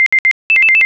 alarm
Alarm sound, can be used to alert of prey or intruders.